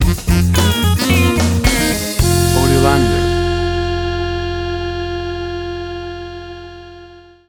Tempo (BPM): 110